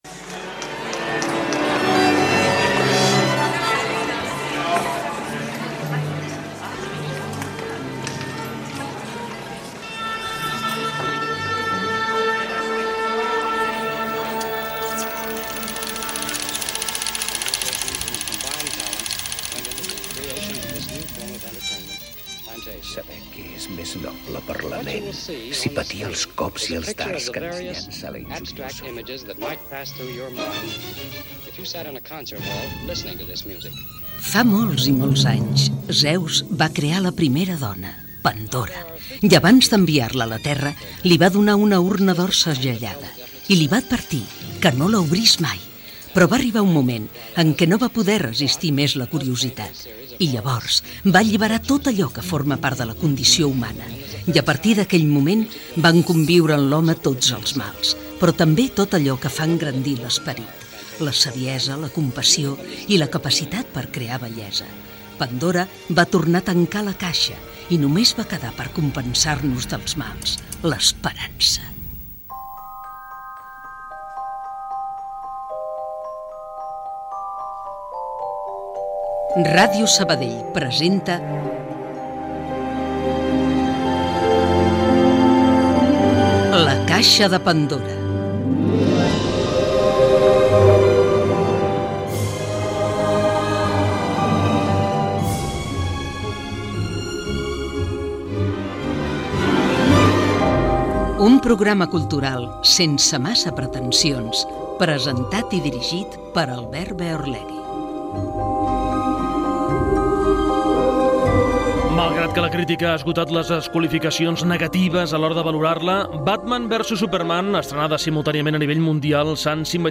Careta i inici del programa. Comentari sobre la pel·lícula "Batman v Superman"
Cultura